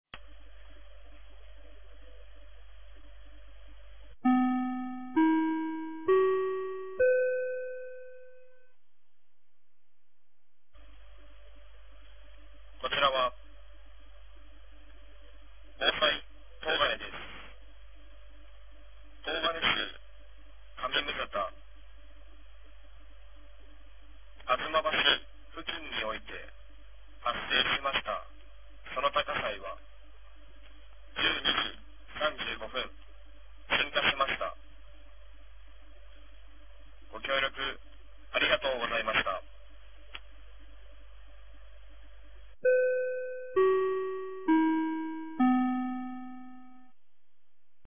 2025年01月10日 12時43分に、東金市より防災行政無線の放送を行いました。